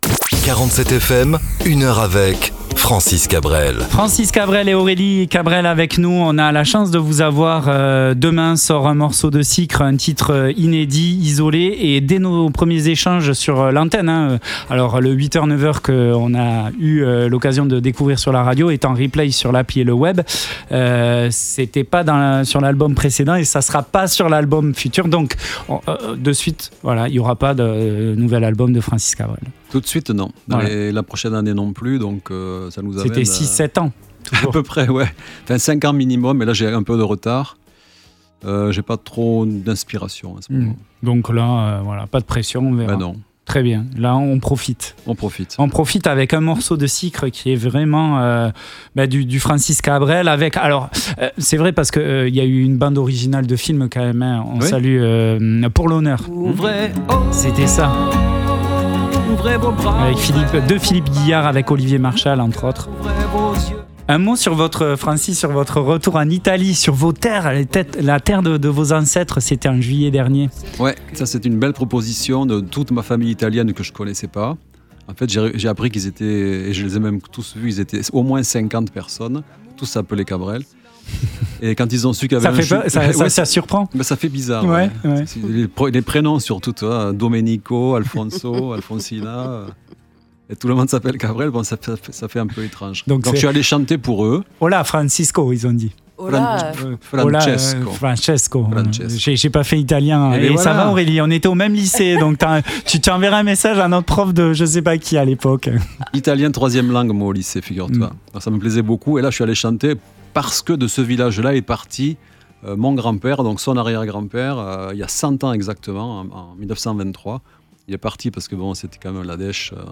Entretien inédit
Lors de leur visite à 47FM, ce jeudi 12 octobre, entre 8h et 9h nous en avons profité pour continuer l'échange !